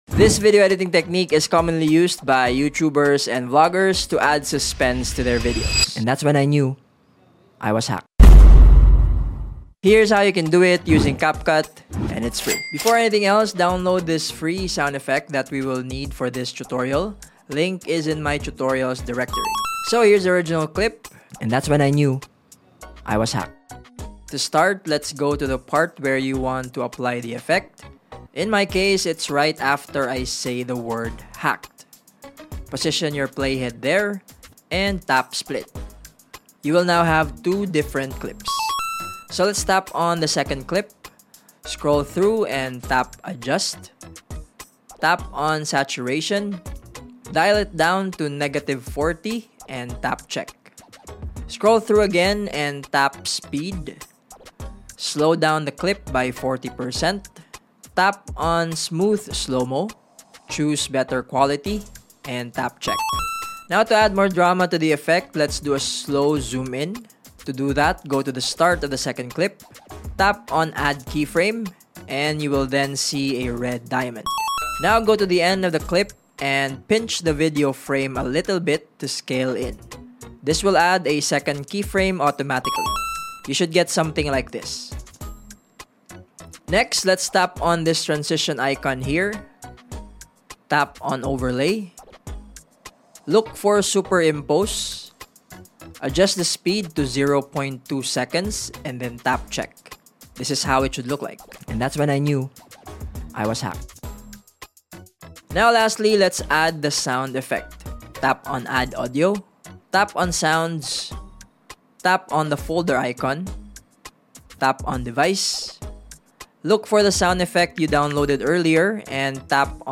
Capcut Tutorial Dramatic Thud sound effects free download